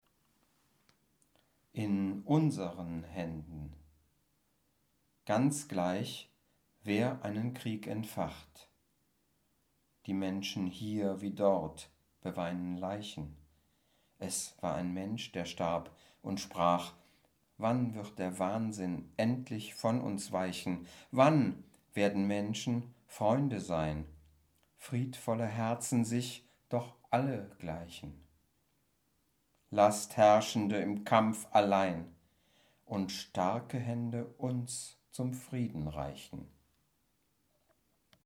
Ballade Erlkönig von Johann Wolfgang von Goethe Rezitation: Erlkönig